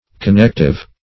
Connective \Con*nect"ive\, a.